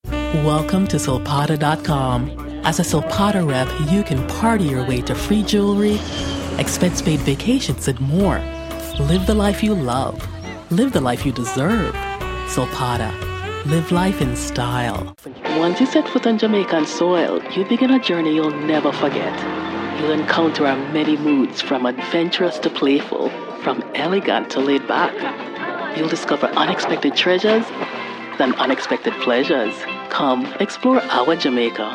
A vibrant, classy, intelligent North American voice talent, specializing in commercial, documentary and corporate narration.
My dual nationality background and international experience brings you a voice with standard American diction that is familiar and comfortable for your international audience.
Sprechprobe: Werbung (Muttersprache):